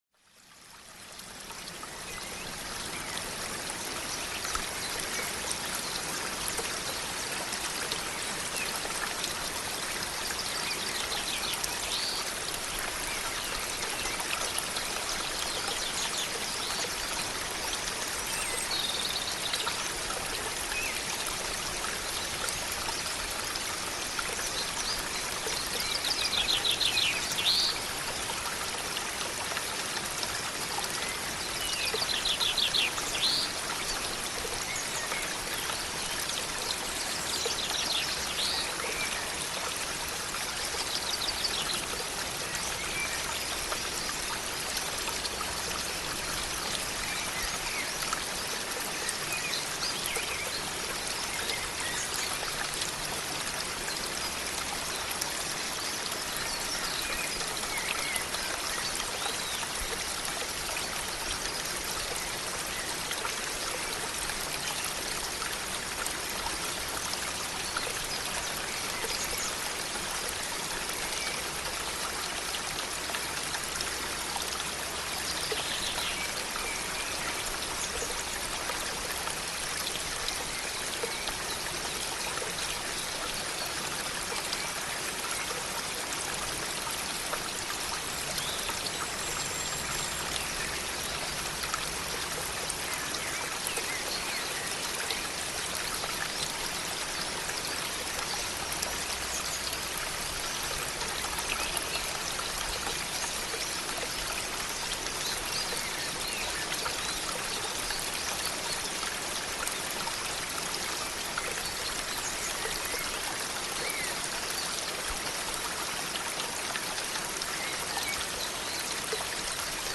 The flow of the water. The light breeze in the trees. The birds, not one species but multiple, all singing their tunes in their own time.
Relaxing_River_Sounds_Forest_Rive_getmp3.pro_.mp3